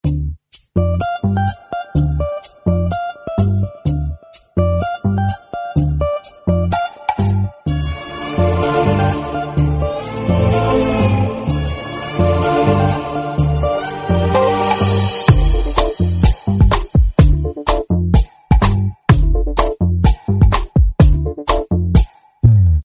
who-incoming-call-sound.DZKCnhgx.mp3